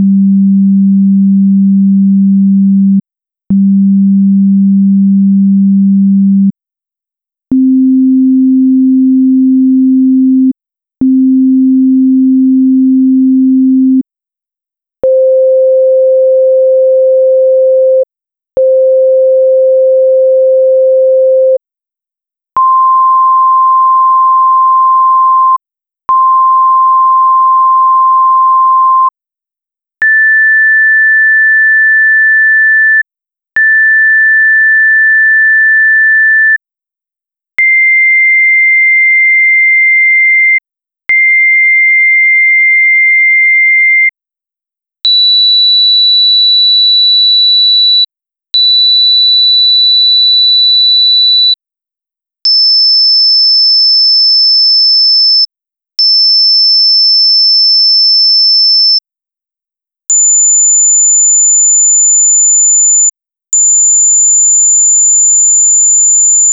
Here are merged files with frequencies from Table 3: Transcendental and Traditional.
You can listen on your music player, or in your browser, and you will see that there is no difference in sound, even at high frequencies.
Side by side Transcendental and Traditional Sound ]
Pair of sounds in order (Transcendental and Traditional): G3 , C4 , C5 , C6 , A6 , C7 , B7 , E8 , A8